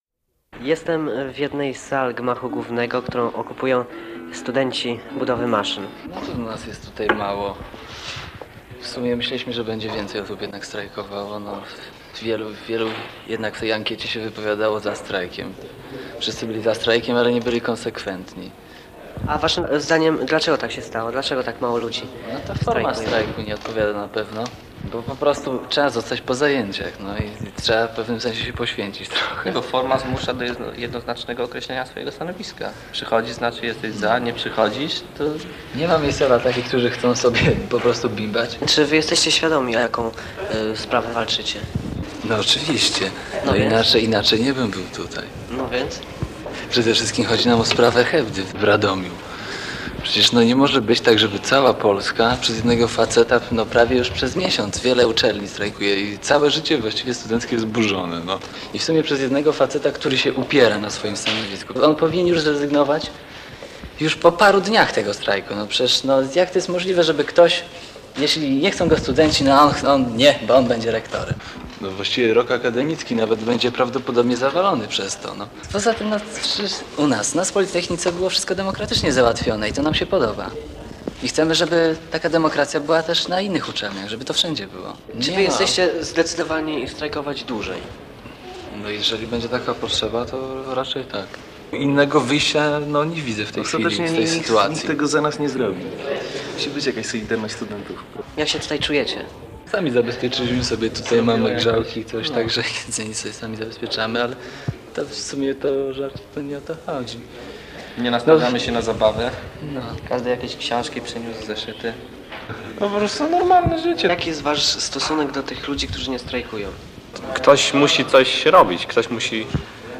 Reportaż ze strajków w UG i PG